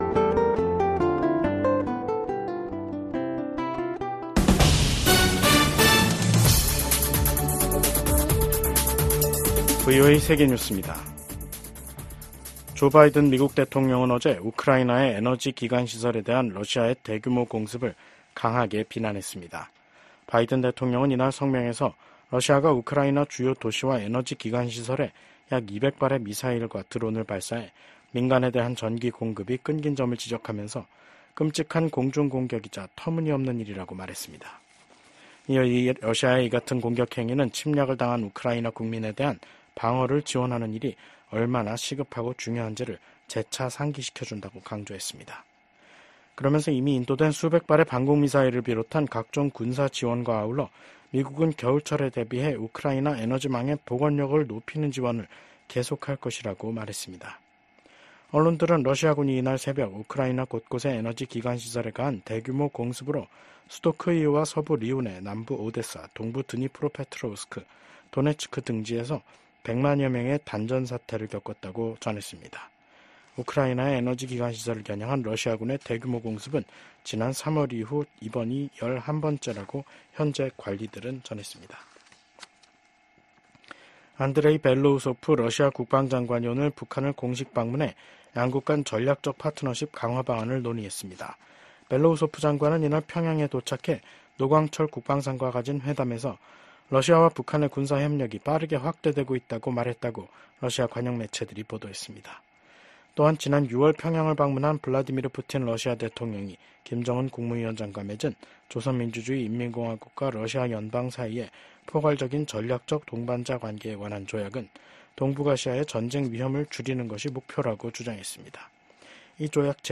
VOA 한국어 간판 뉴스 프로그램 '뉴스 투데이', 2024년 11월 29일 2부 방송입니다. 우크라이나와의 전쟁이 치열해지는 가운데 러시아의 안드레이 벨로우소프 국방장관이 북한을 공식 방문했습니다. 미국 국무부가 북한 국적자를 포함한 개인 3명과 러시아, 중국 회사에 제재를 단행했습니다. 북한과 중국이 강제 북송된 탈북민을 강제 노동에 동원해 경제적 이익을 공유하고 있다는 연구 결과가 나왔습니다.